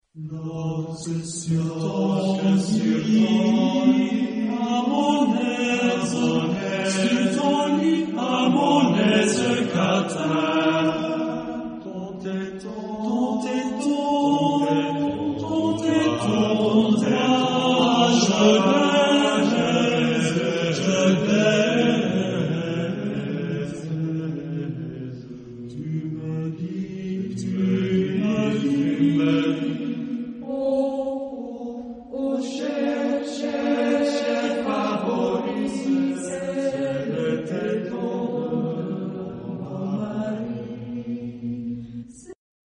Genre-Style-Form: Secular ; Contemporary ; Partsong
Mood of the piece: joyous ; humorous
Type of Choir: SATB  (4 mixed voices )
Tonality: E major